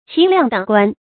齐量等观 qí liàng děng guān
齐量等观发音
成语注音ㄑㄧˊ ㄌㄧㄤˋ ㄉㄥˇ ㄍㄨㄢ